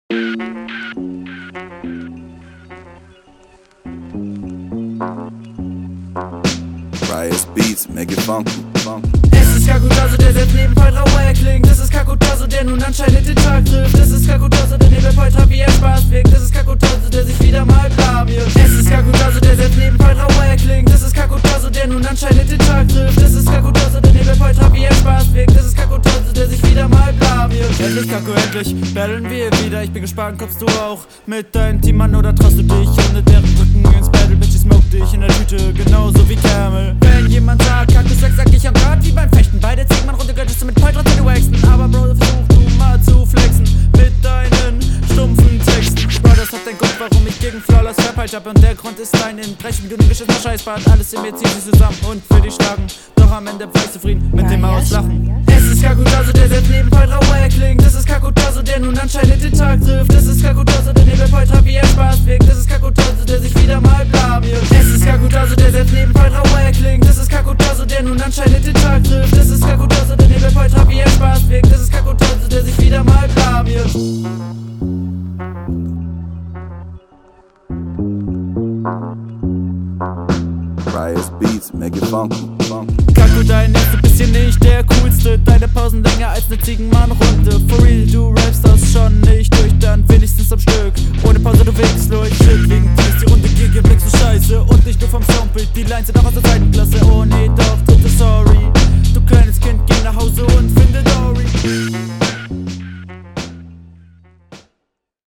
Tipp für Doubletime: Spars dir.
Dein Gegner hat Recht: Neues Mic, aber trotzdem beschissenes Sounderlebnis.